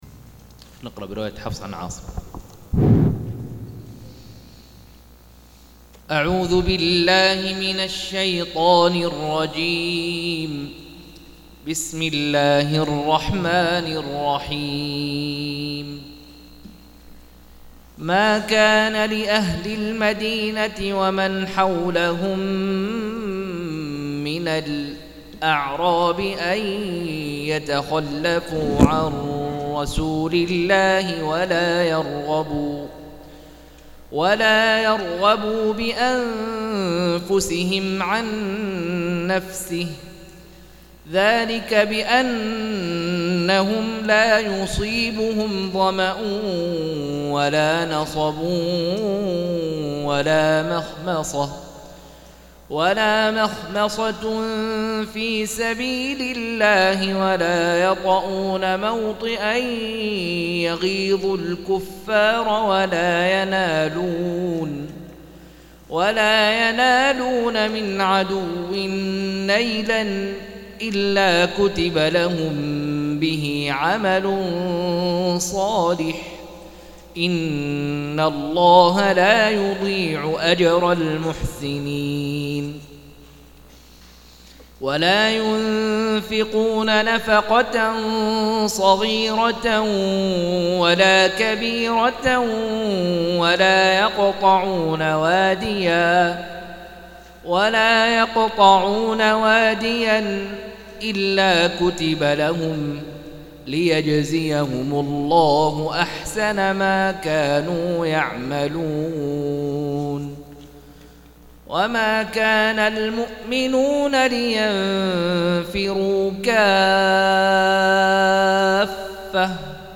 196- عمدة التفسير عن الحافظ ابن كثير رحمه الله للعلامة أحمد شاكر رحمه الله – قراءة وتعليق –